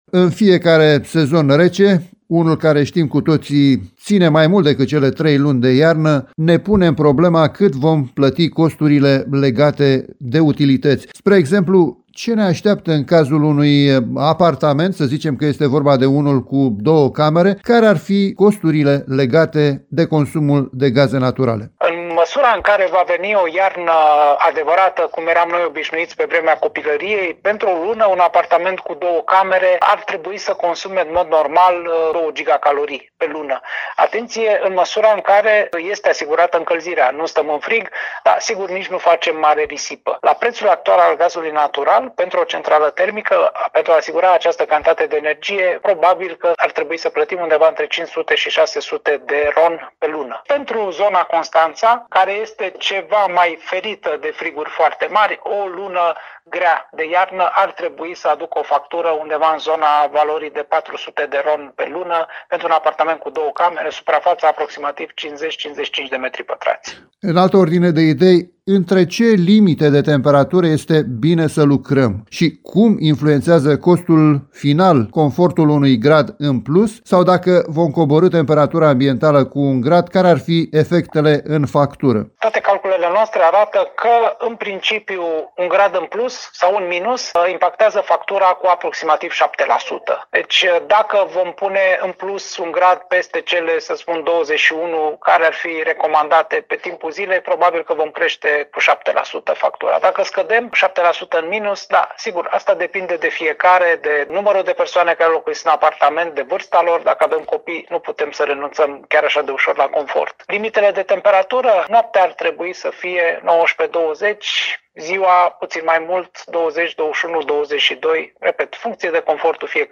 specialist în energie, în dialog cu